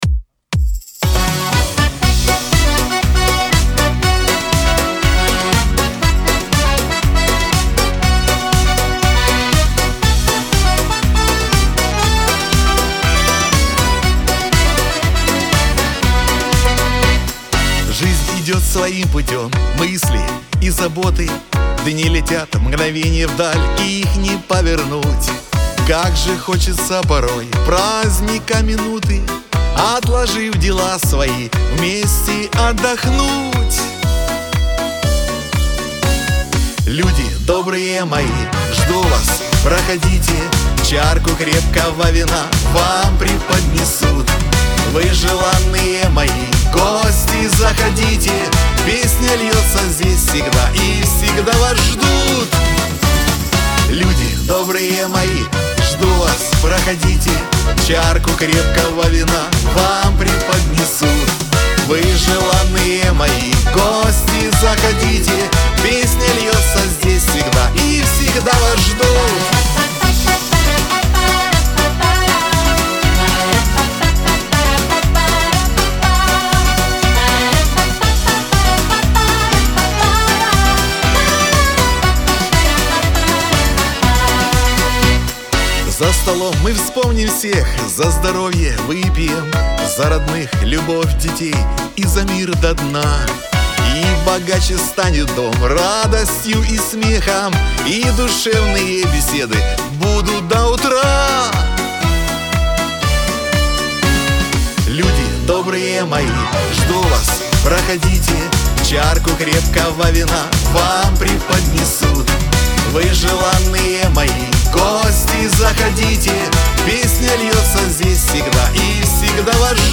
Лирика , Шансон